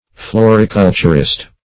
floriculturist - definition of floriculturist - synonyms, pronunciation, spelling from Free Dictionary
Search Result for " floriculturist" : The Collaborative International Dictionary of English v.0.48: Floriculturist \Flo`ri*cul"tur*ist\, n. One skilled in the cultivation of flowers; a florist.